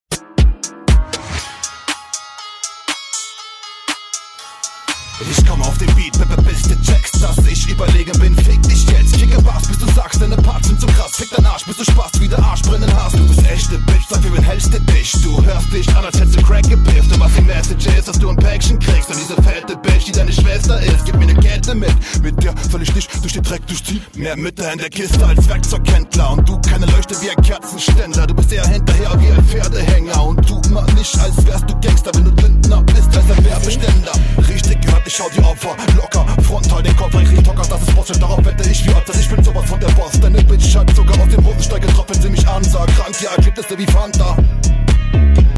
Beat und Flow gefällt mir hier sehr gut, du strengst dich an das merkt man.
Der Flow und die Betonungen sind direkt ziemlich gut, Props!
Krasser beat, flowst gut darauf, schön Aggresiv mit vielen Punchlines.